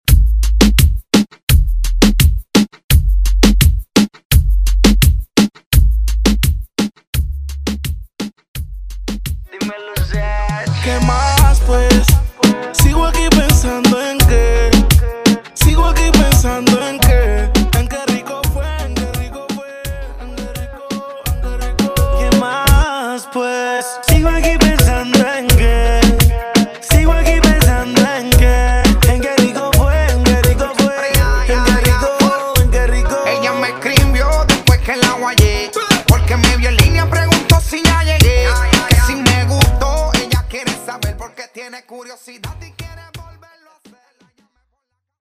perfect for DJs and Latin music lovers. dj remixes